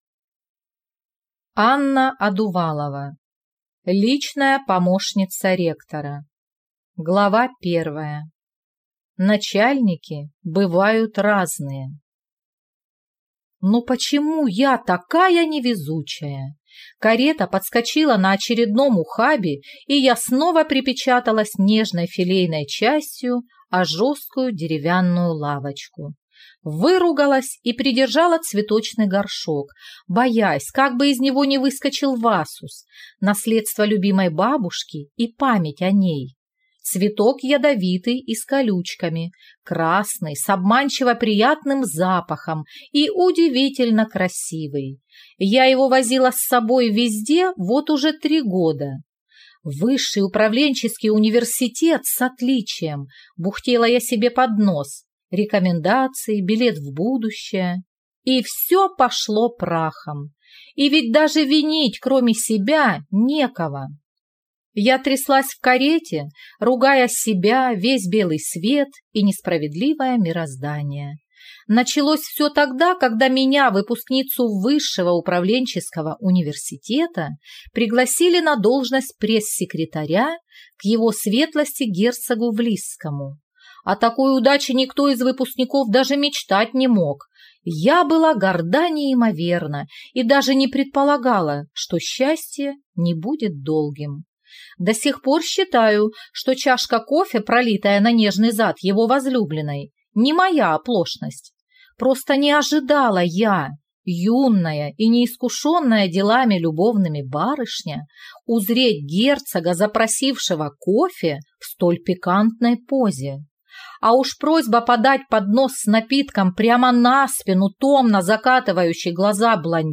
Aудиокнига Личная помощница ректора